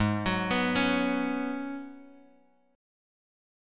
G#m11 chord